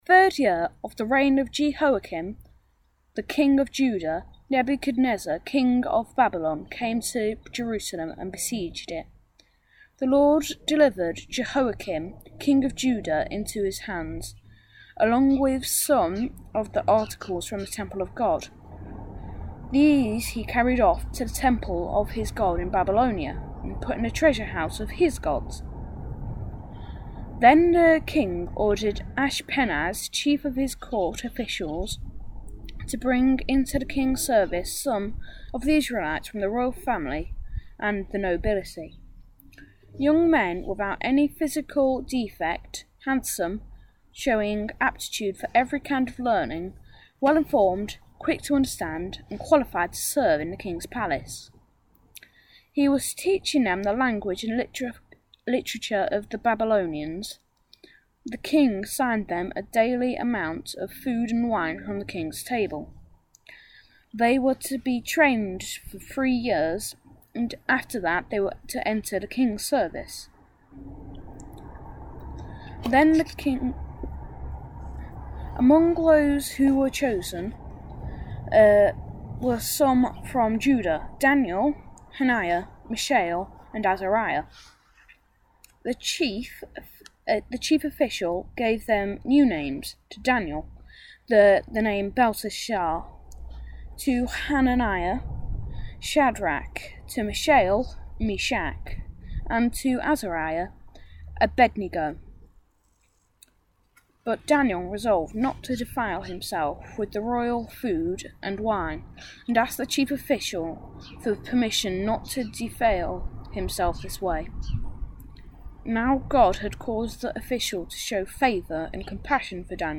NBC-Worship-5th-July-2020.mp3